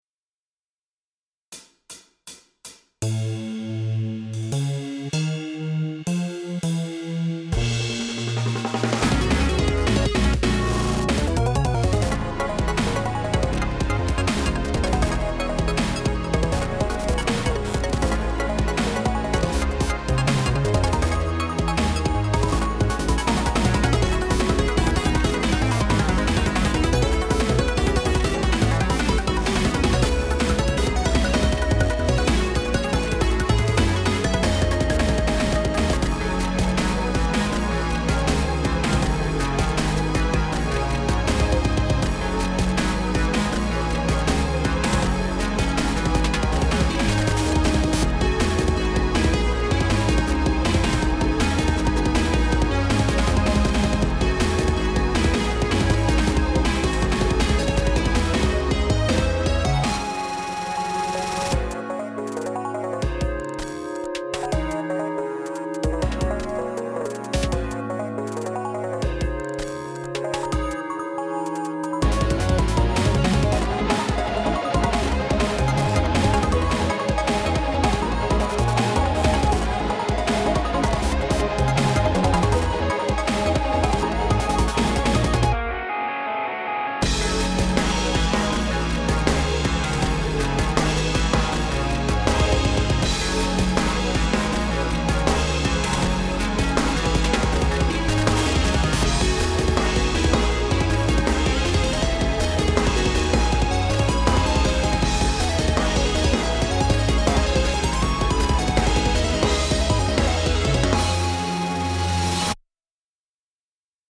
バッキングトラック